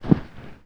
IMPACT_Generic_Soft_Muffled_mono.wav